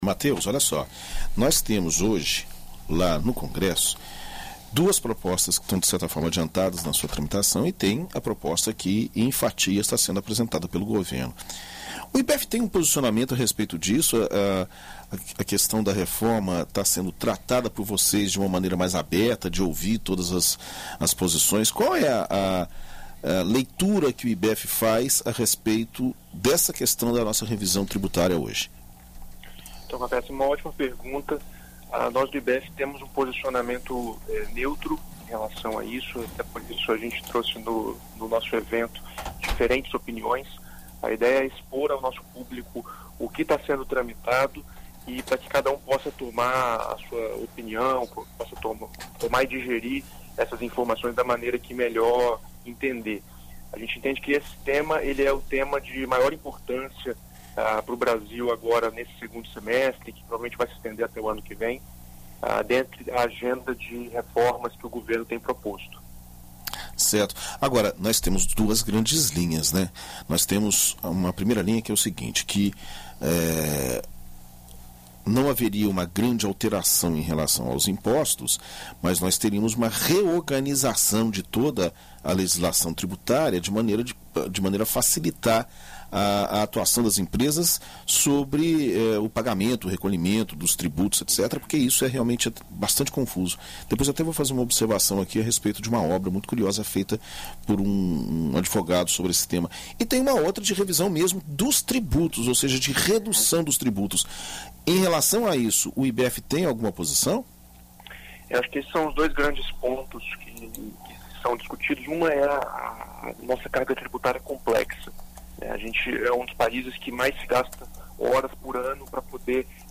Em entrevista à BandNews FM Espírito Santo nesta terça-feira (25)